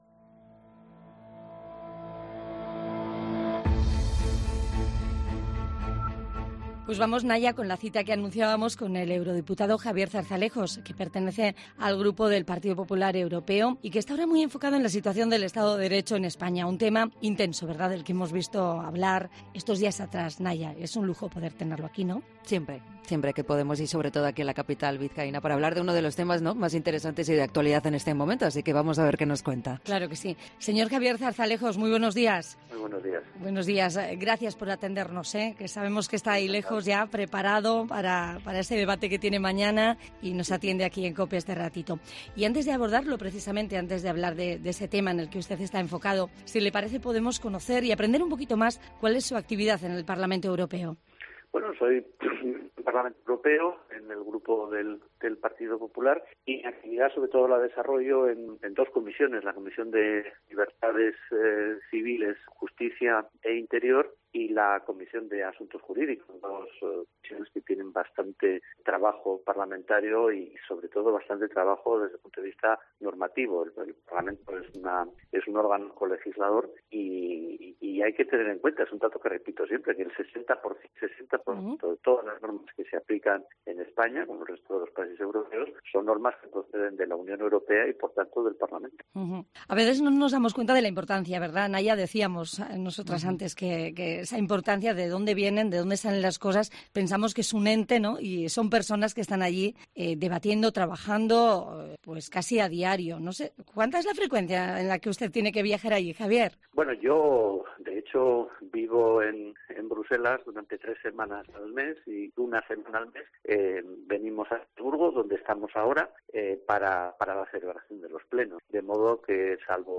Entrevista a Javier Zarzalejos, en COPE Euskadi